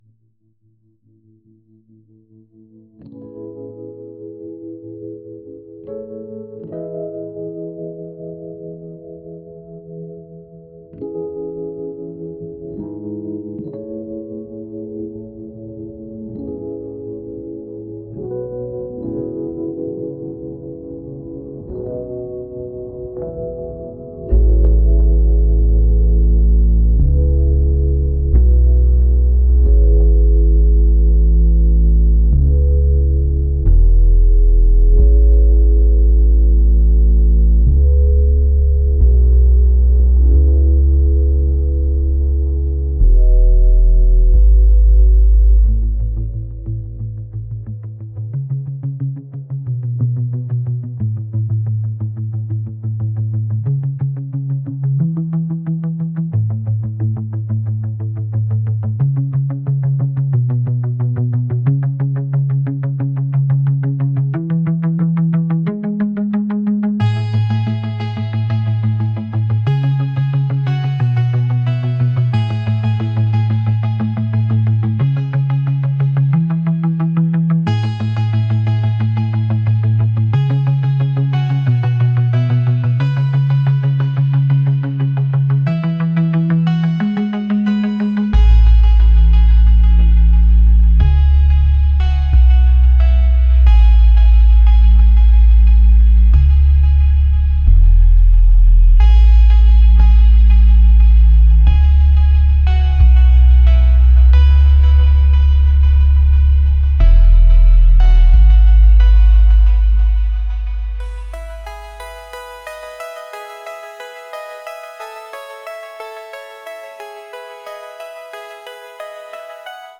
pop | acoustic | lofi & chill beats